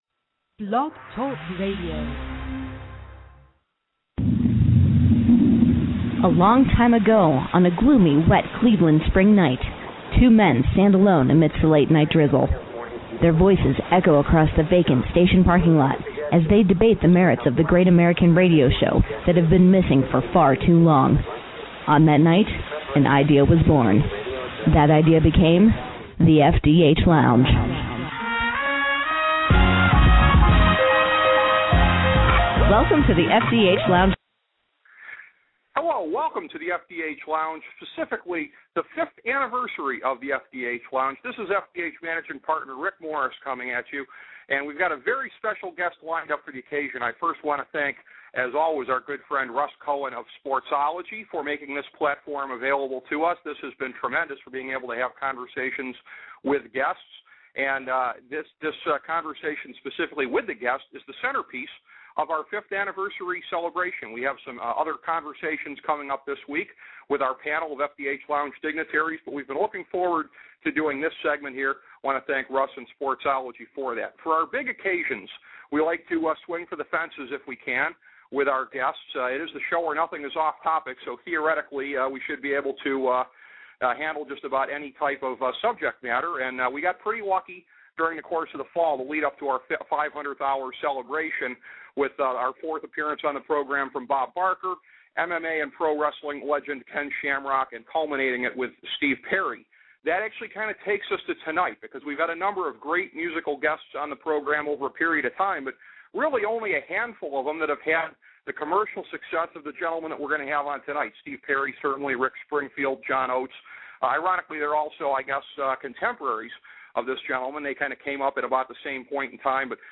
A conversation with 5-time Grammy winner Christopher Cross